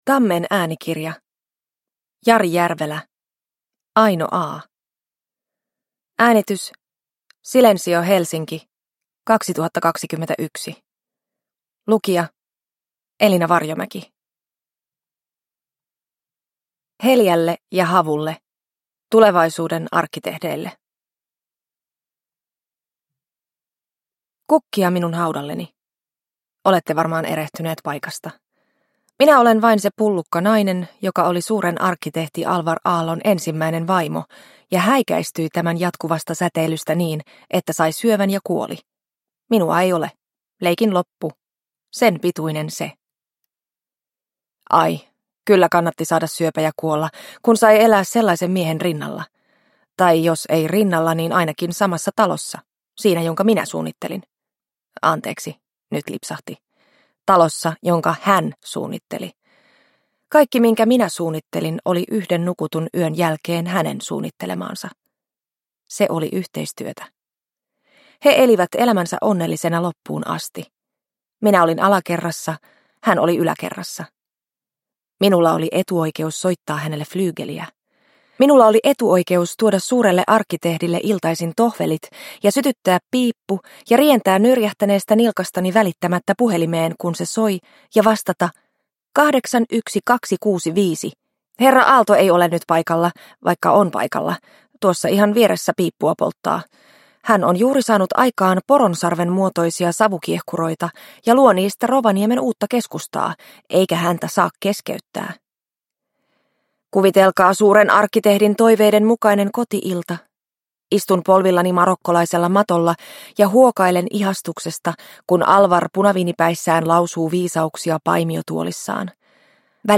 Aino A. – Ljudbok – Laddas ner